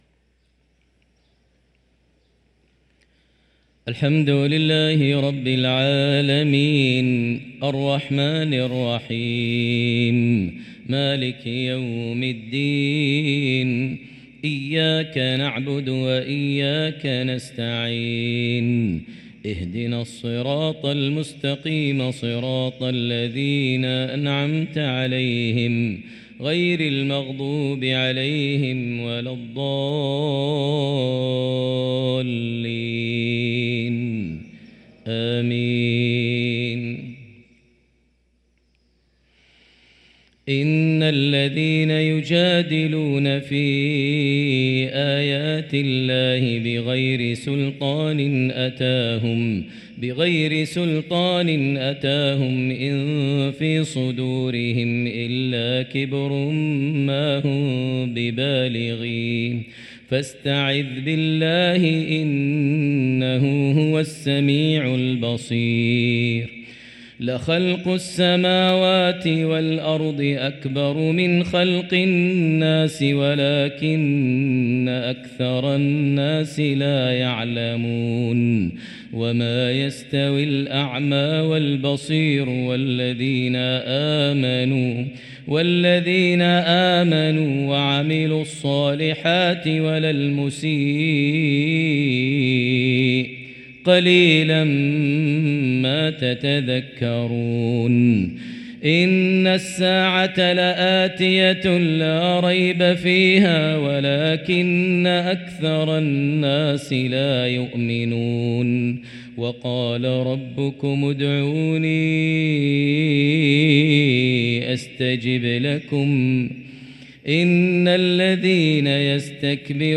صلاة العشاء للقارئ ماهر المعيقلي 30 جمادي الآخر 1445 هـ
تِلَاوَات الْحَرَمَيْن .